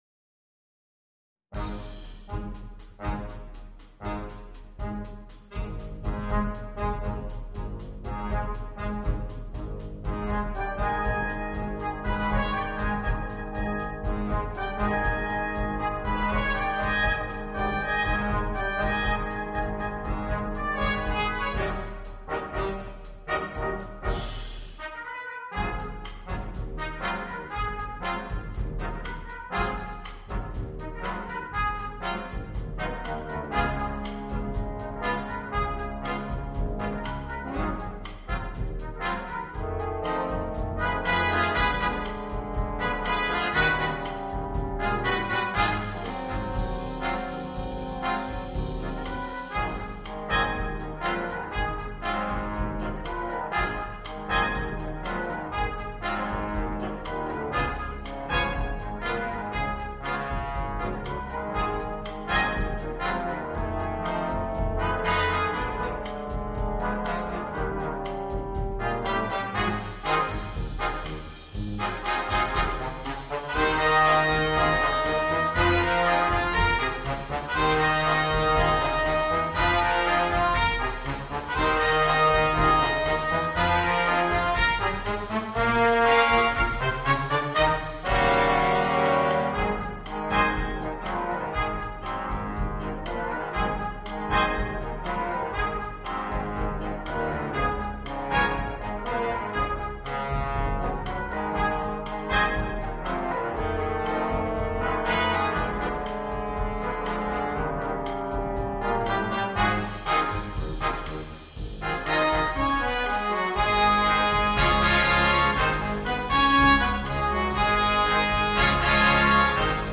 для брасс-бэнда